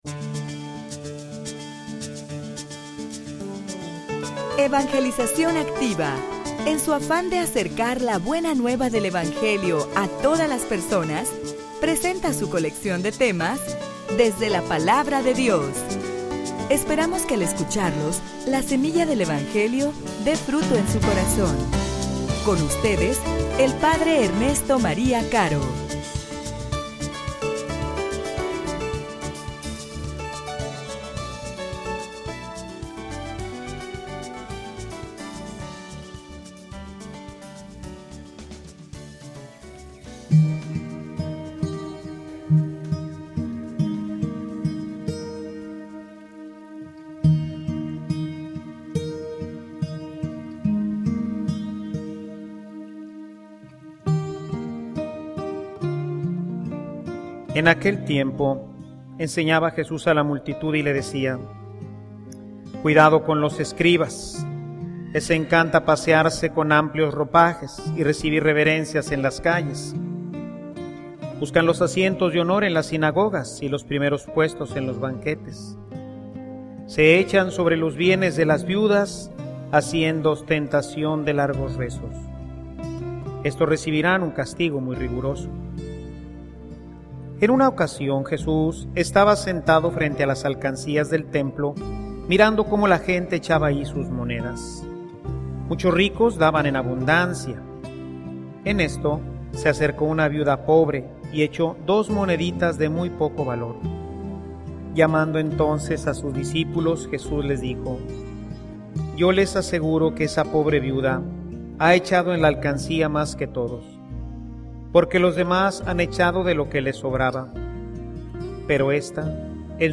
homilia_Fe_que_se_convierte_en_confianza.mp3